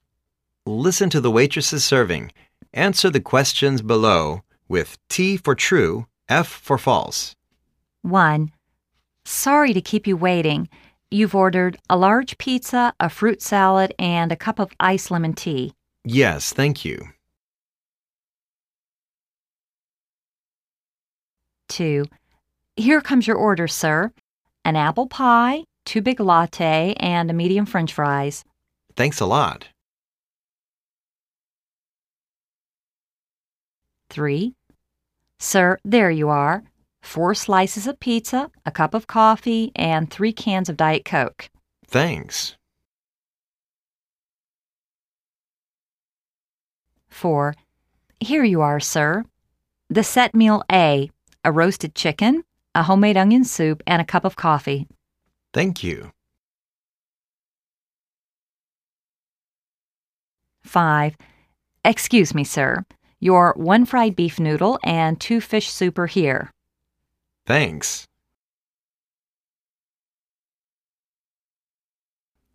Listen to the waitress' serving.
听MP3中的服务生上菜，回答下列的是非题。